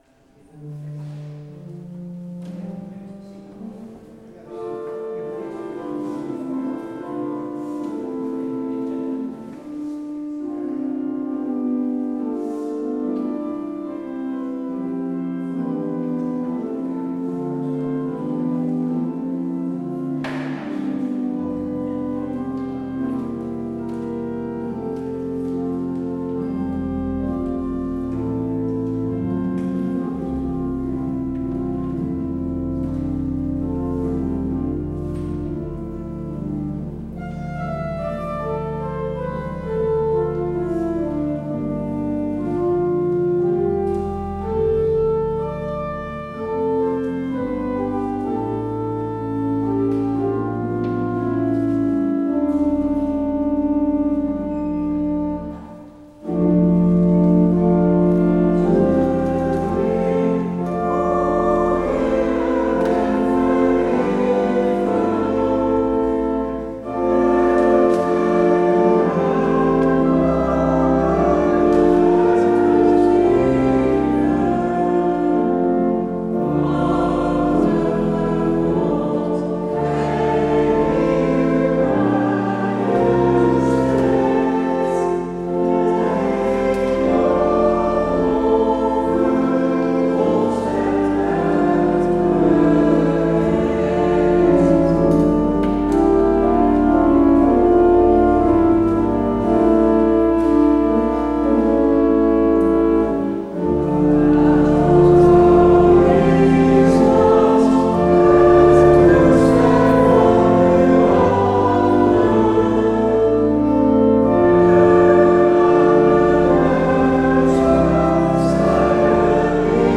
Luister deze kerkdienst hier terug: